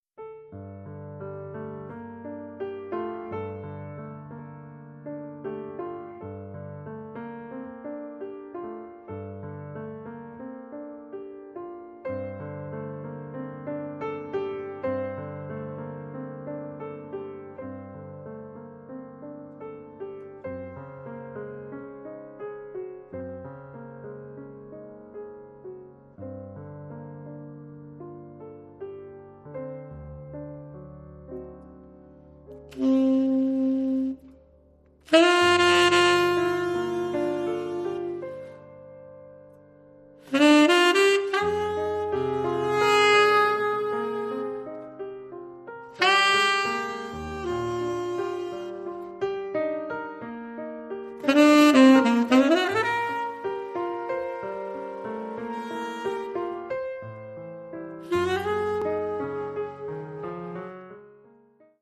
pianoforte
sax tenore